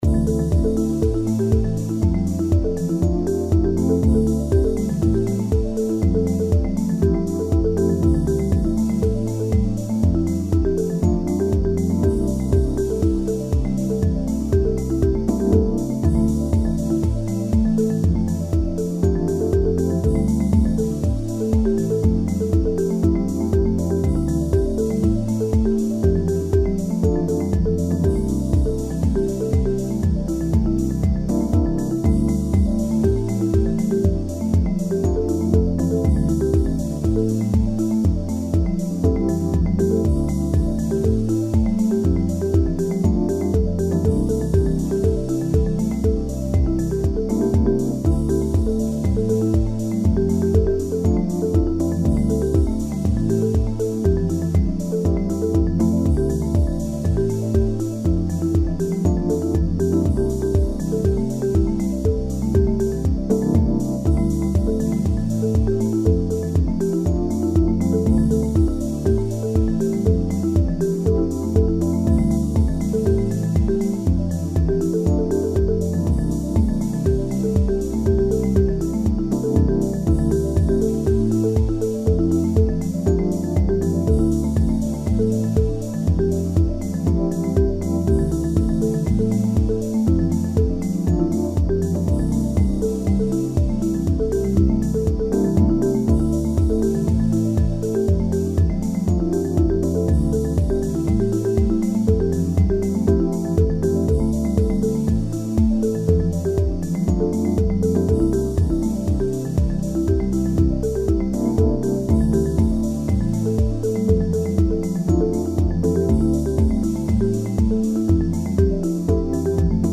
The marimba was made for randomly arpeggiating